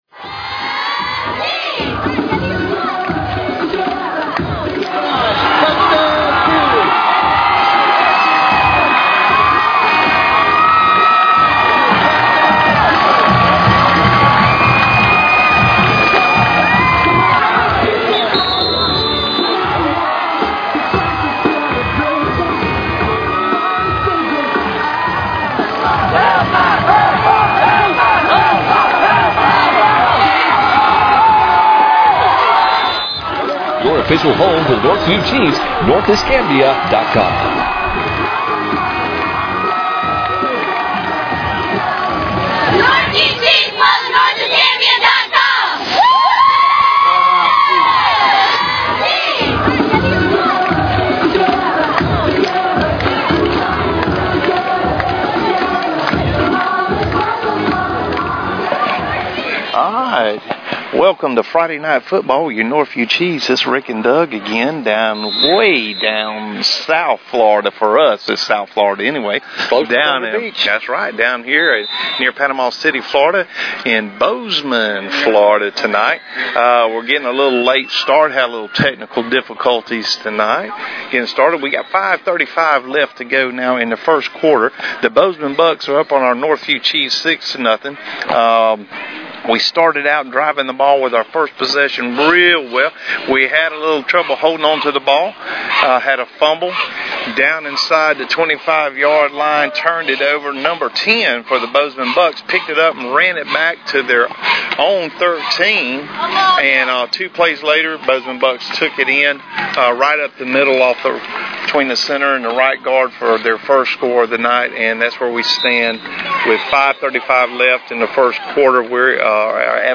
To download the MP3 audio from the September 25 Northview High School game against Bozeman, click here.